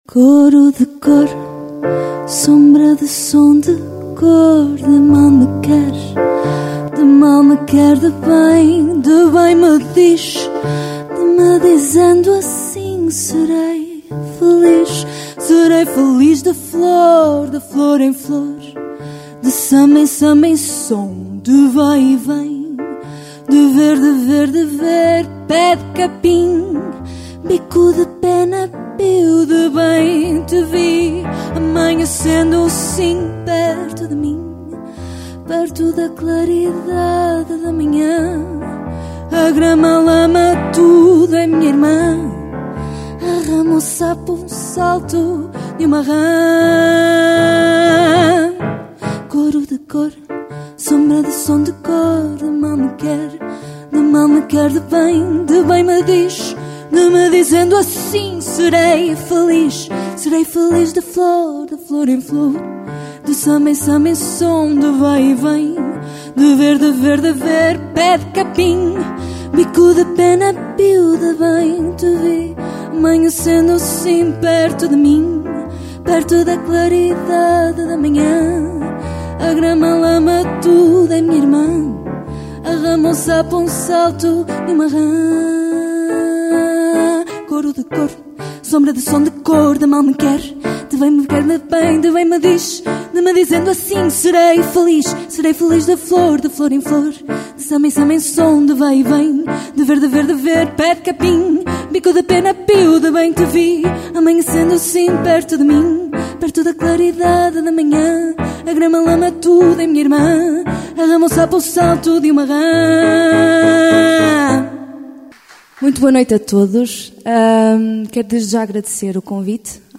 A iniciativa decorreu no auditório da Casa da Junta de S. João – Polo de Inovação e Capacitação Social, na passada sexta-feira, dia 25 de março, com início às 21h00.
Cancro 03 Momento musical - 128  kpbs.mp3